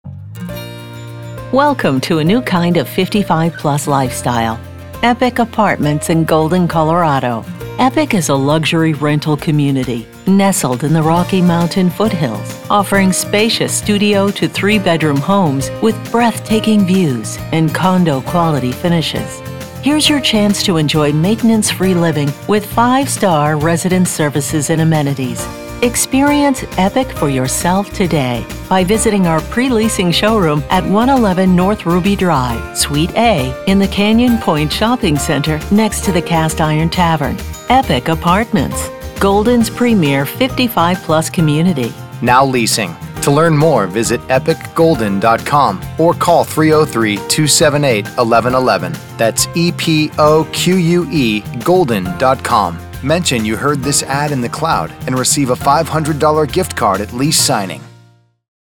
Female – Light, Happy, Uplifting